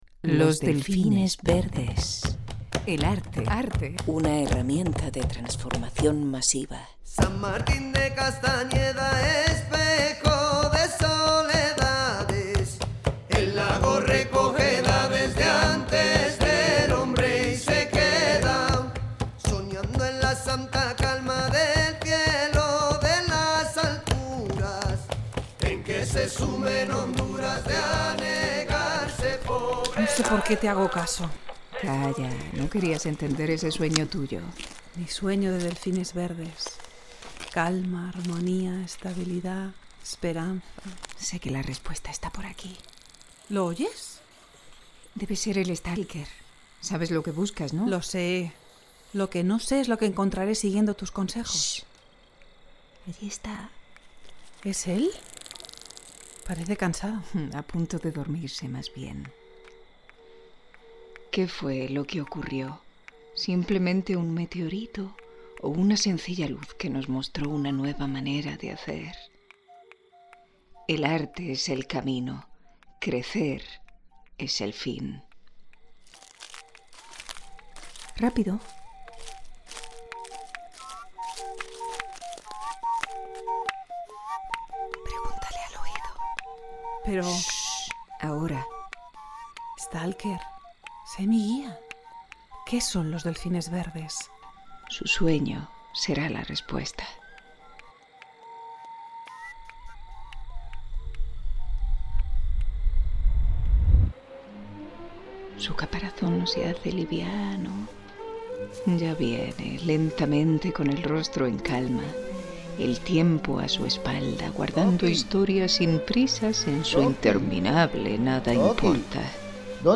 El primer episodio de nuestro pódcast es un sueño convertido en ficción.
Música de la careta (entrada y salida)